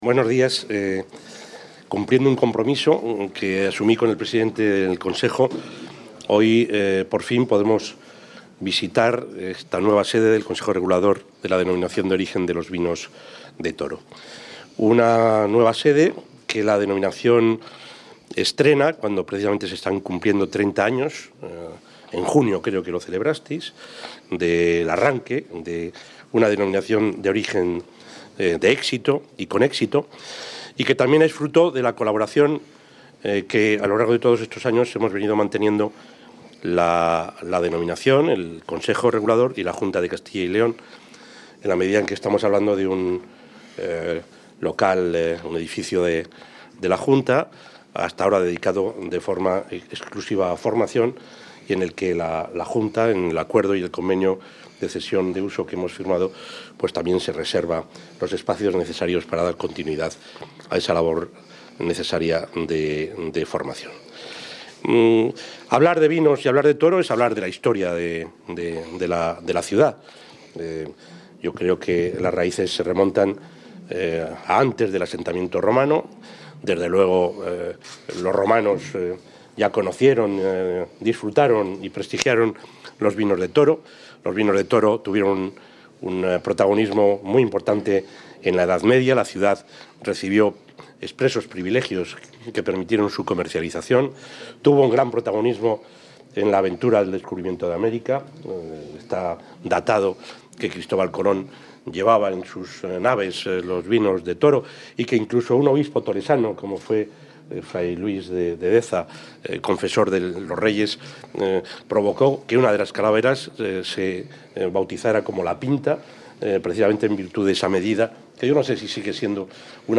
Intervención del presidente de la Junta.
El presidente de la Junta de Castilla y León, Juan Vicente Herrera, ha visitado hoy las nuevas instalaciones de la Denominación de Origen Toro, donde ha destacado el buen momento que viven los vinos acogidos a esta DO, que este año celebra su trigésimo aniversario, así como el conjunto del sector vitivinícola.